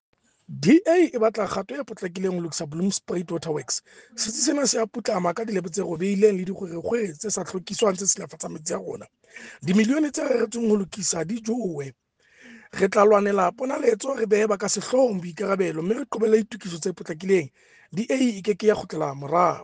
Sesotho soundbite by Cllr Kabelo Mooreng.